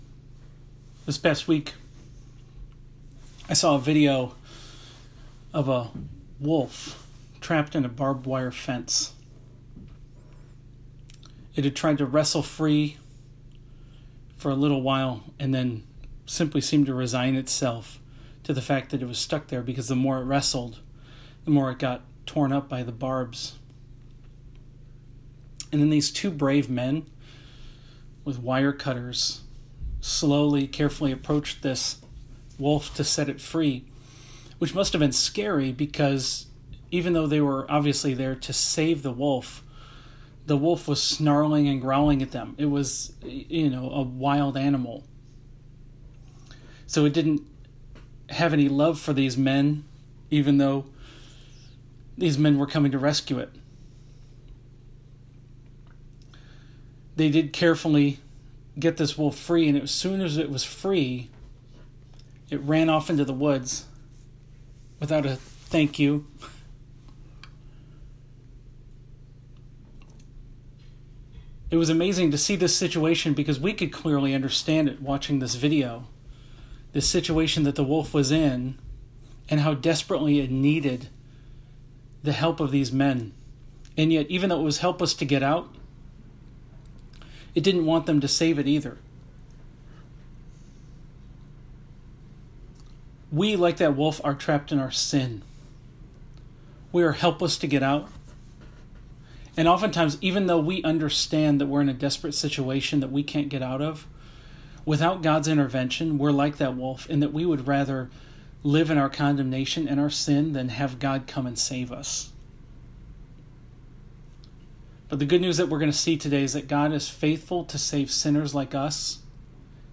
Download Audio Home Resources Sermons God Saves the Undeserving